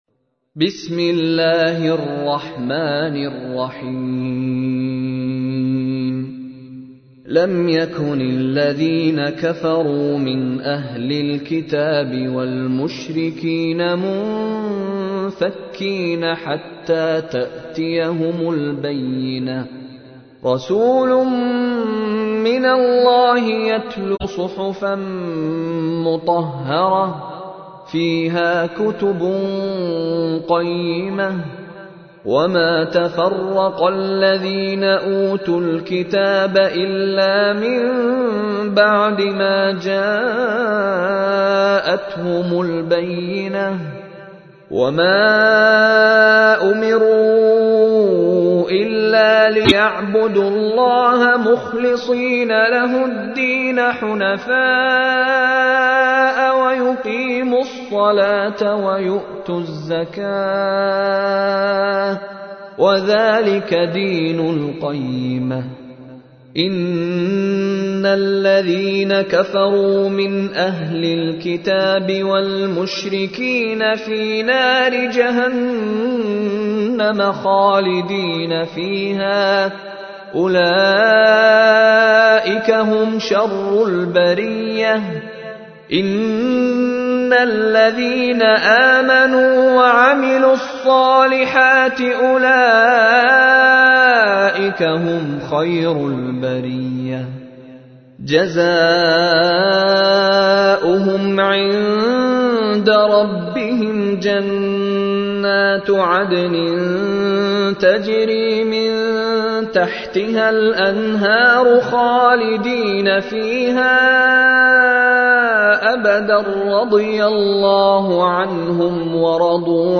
تحميل : 98. سورة البينة / القارئ مشاري راشد العفاسي / القرآن الكريم / موقع يا حسين